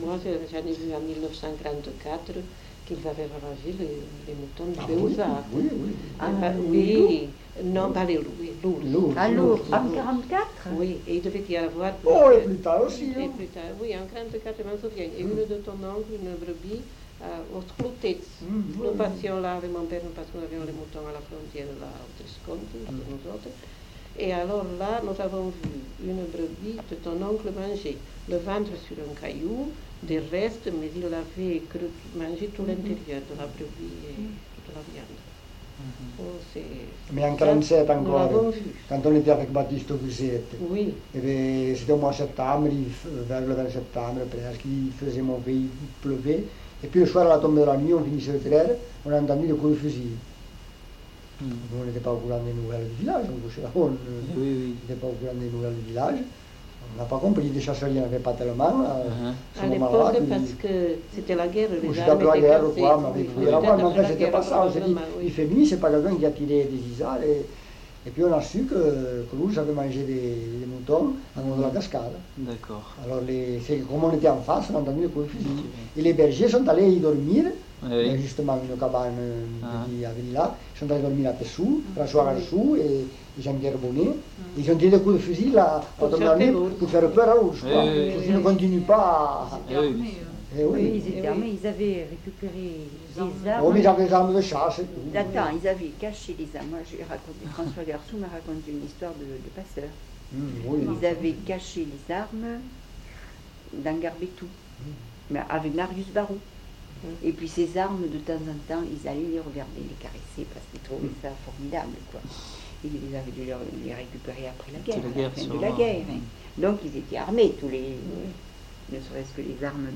Lieu : Aulus-les-Bains
Genre : témoignage thématique